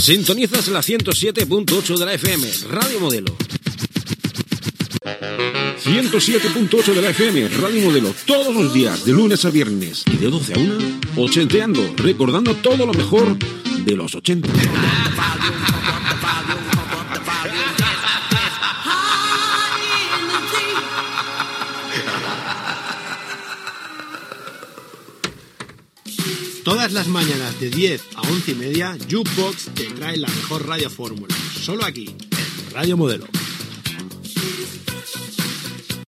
b17b1241a548fc96c586479efaefd39d4458a75a.mp3 Títol Radio Modelo Emissora Radio Modelo Titularitat Tercer sector Tercer sector Penitenciària Descripció Identificació de l'emissora i promocions dels programes "Ochenteando" i "Jukebox".